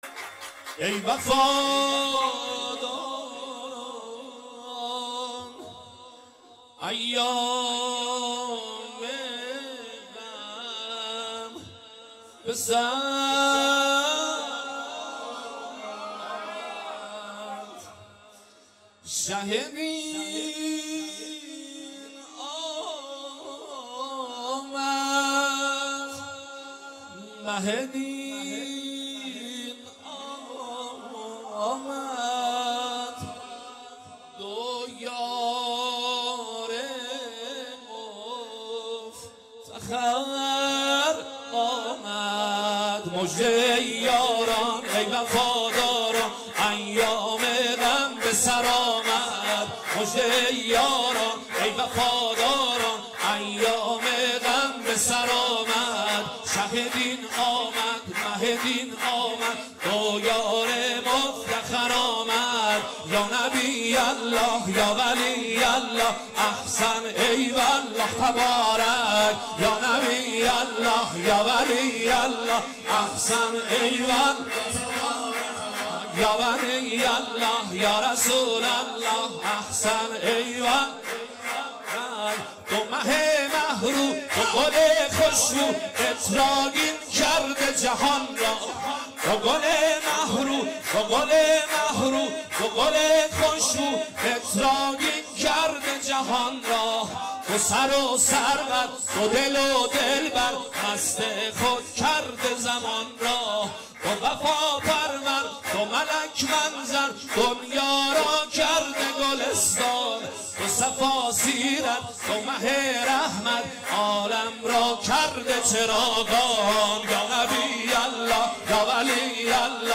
ولادت پیامبر اکرم (ص)وامام صادق(ع)96